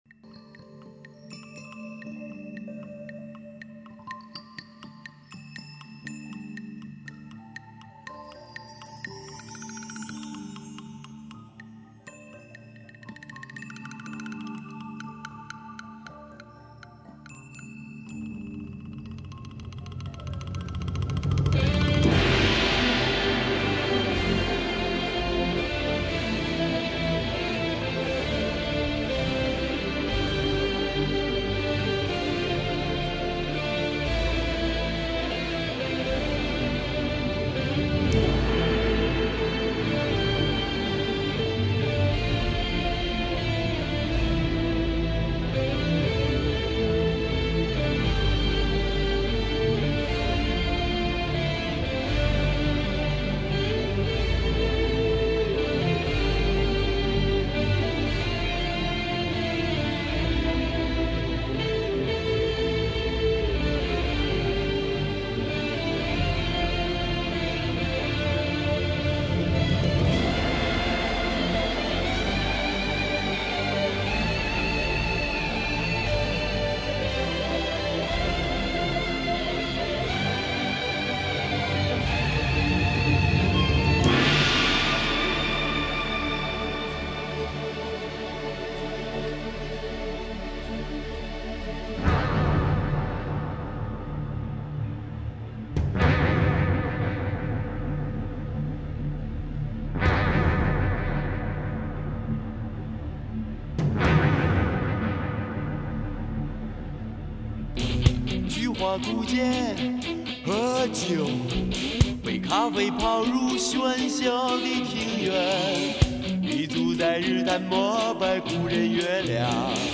评中，可以看得出来它是出色的，开创了中国摇滚的先河。